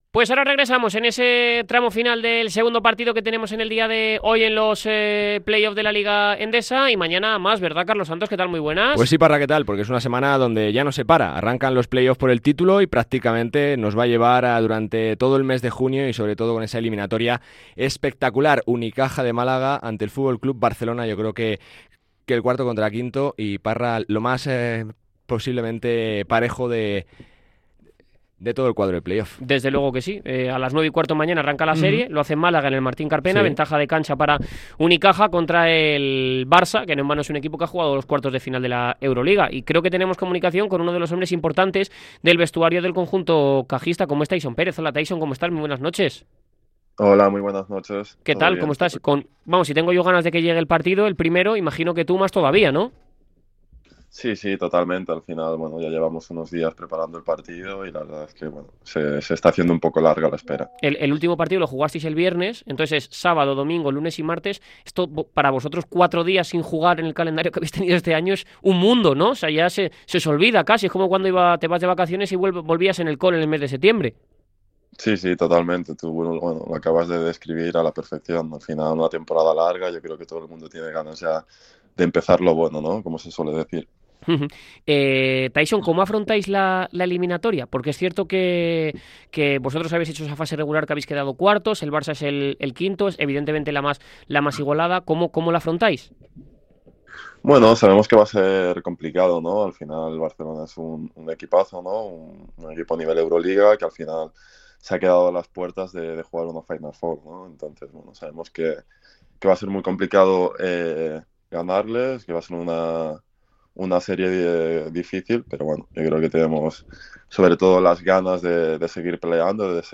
Por su parte, Tyson Pérez ha sido el protagonista que se ha puesto delante del micrófono rojo de Radio MARCA. El hispanodominicano ha analizado en la previa al choque todo el contexto. Desde sus ganas hasta las claves para batir a los de Peñarroya.